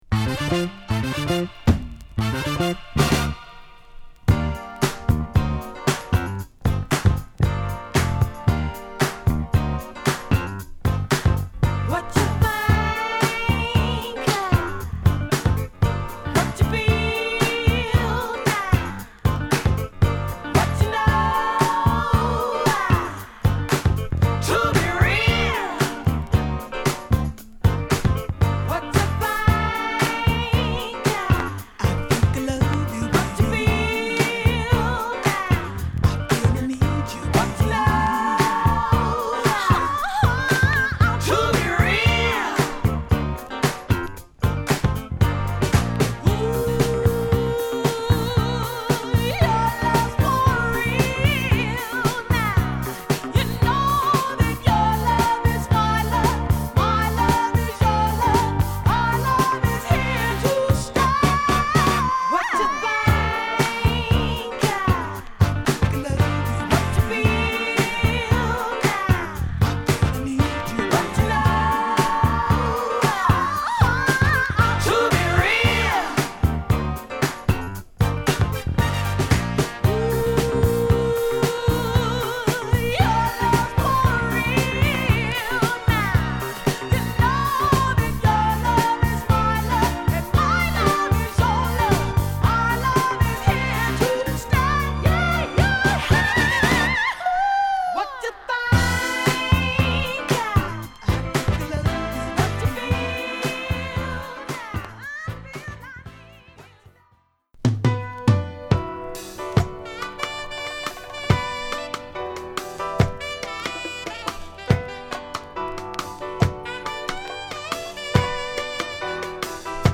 L.A.出身の女性シンガー
Hip Hop〜ダンスクラシック／ディスコで愛される1曲！